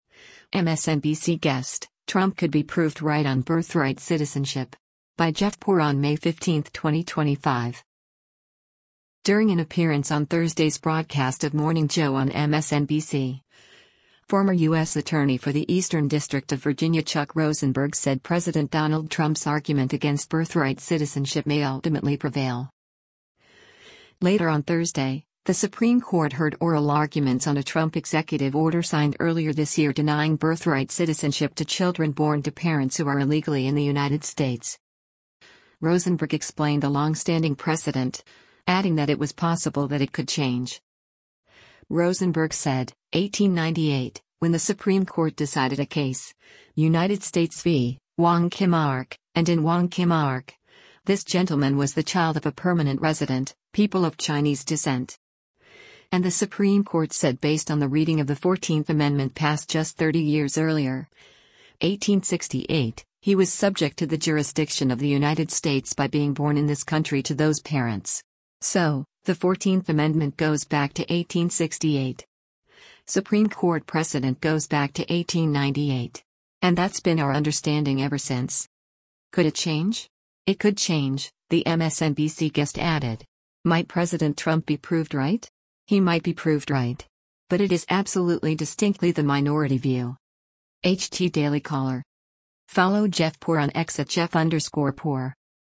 During an appearance on Thursday’s broadcast of “Morning Joe” on MSNBC, former U.S. Attorney for the Eastern District of Virginia Chuck Rosenberg said President Donald Trump’s argument against birthright citizenship may ultimately prevail.